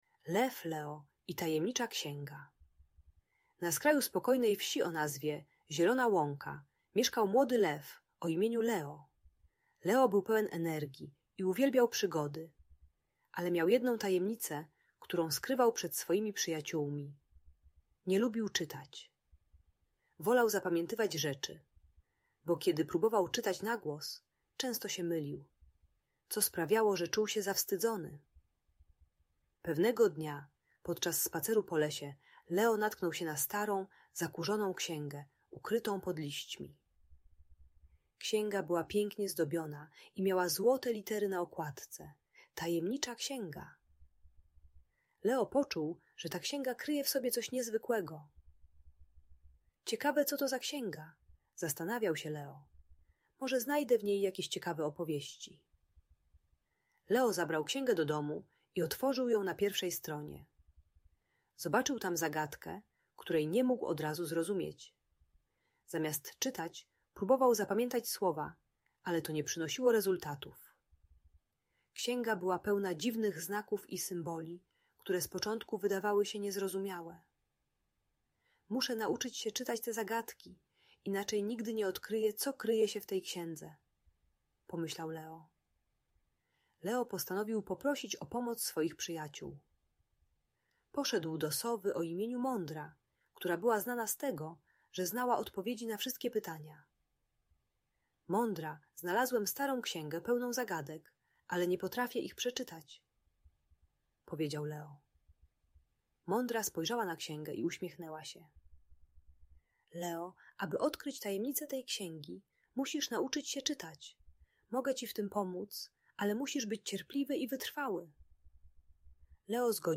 Lew Leo i Tajemnicza Księga - Szkoła | Audiobajka